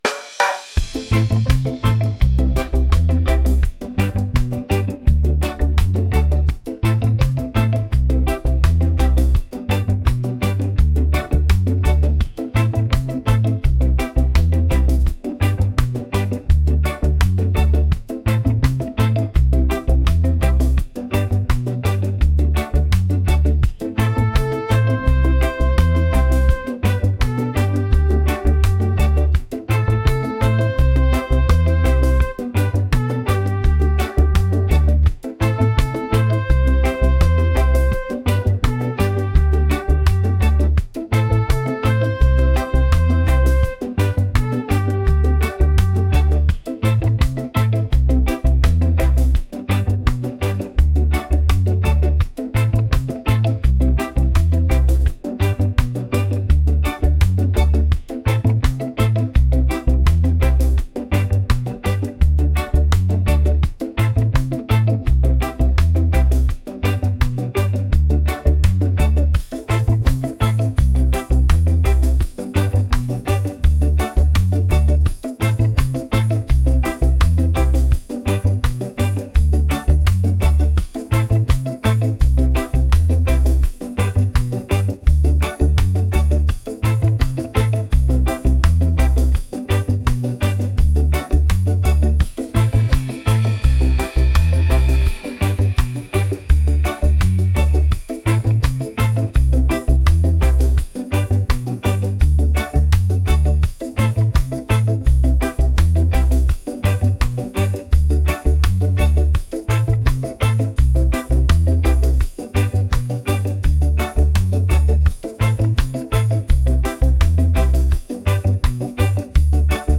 funky | upbeat | reggae